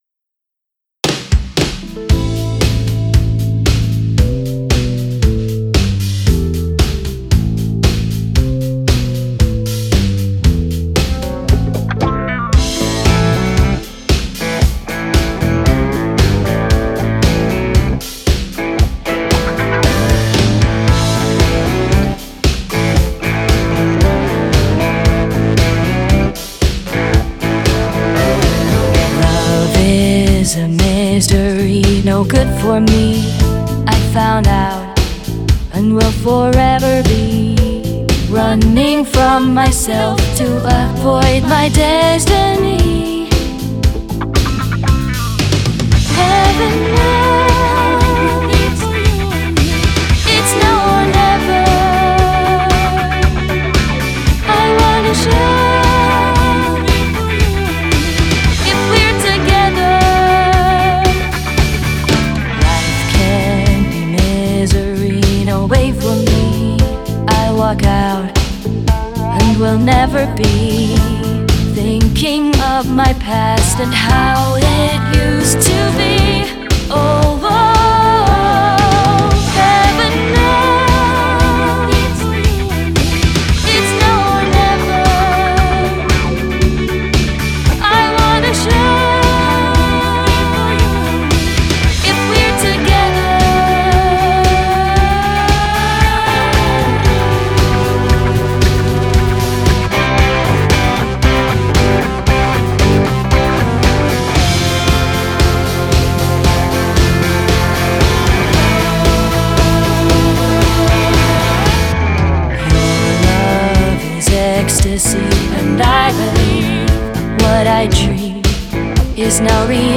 Heaven Knows AI Generated Audio And Generative AI Music Video
Bass, guitar, keyboard